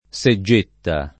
seggetta [ S e JJ% tta ]